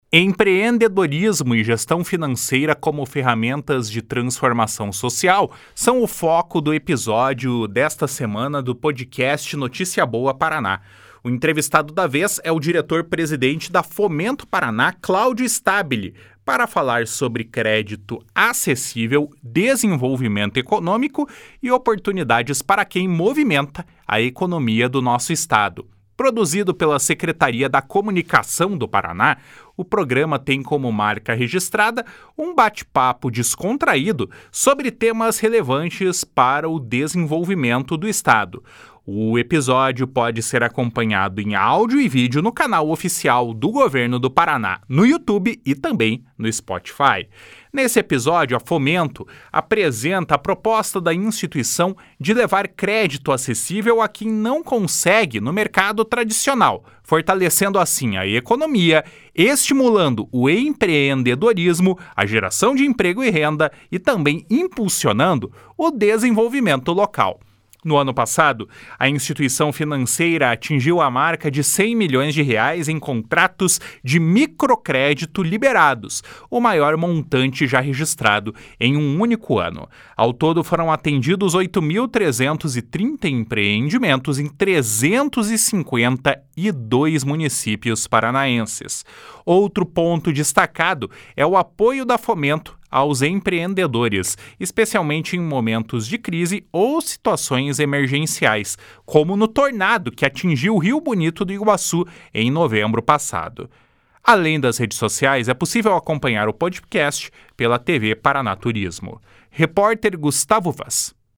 Empreendedorismo e gestão financeira como ferramentas de transformação social são o foco do episódio desta semana do podcast Notícia Boa Paraná. O entrevistado da vez é o diretor-presidente da Fomento Paraná, Claudio Stabile, para falar sobre crédito acessível, desenvolvimento econômico e oportunidades para quem movimenta a economia do Estado. Produzido pela Secretaria de Estado da Comunicação, o programa tem como marca registrada um bate-papo descontraído sobre temas relevantes para o desenvolvimento do Estado.